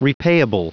Prononciation du mot repayable en anglais (fichier audio)
Prononciation du mot : repayable